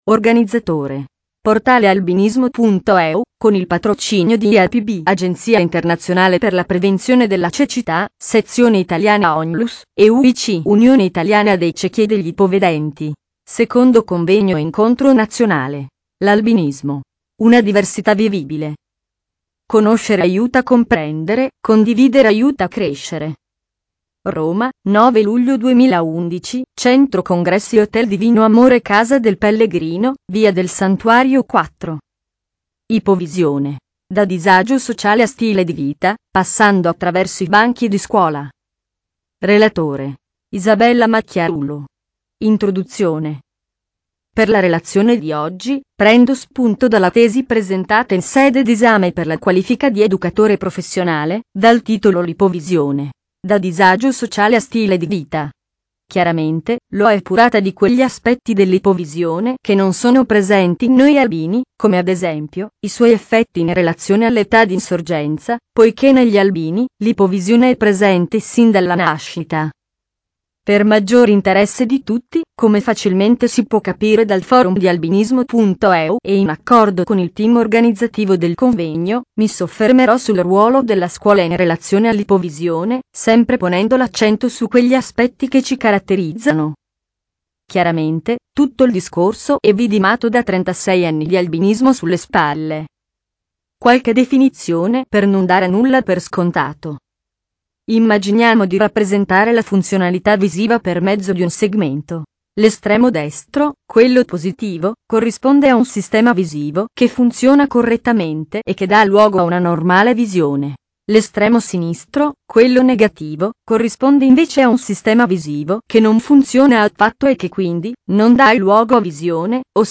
in occasione del 2� Convegno Nazionale sull'Albinismo